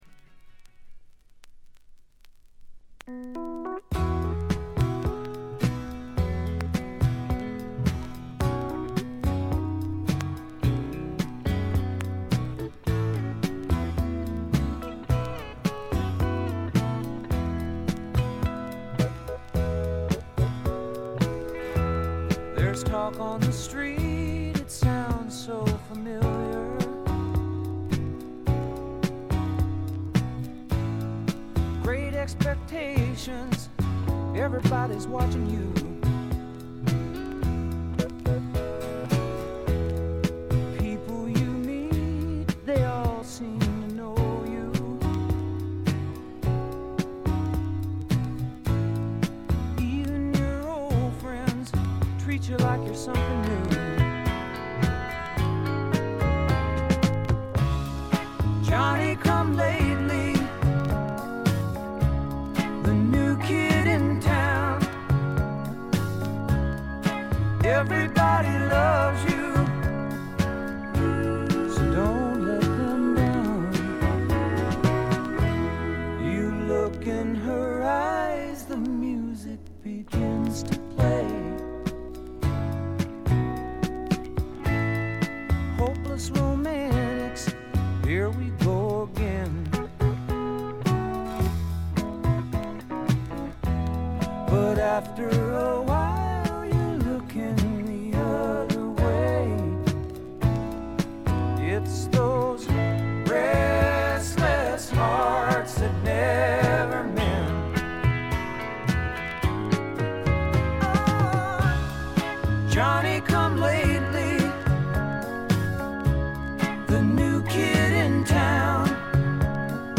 ところどころでチリプチ、静音部（A面イントロ等）でやや目立ちますが普通に鑑賞できるレベルと思います。
盤質B評価とはしましたが普通に聴けるレベルと思います。
試聴曲は現品からの取り込み音源です。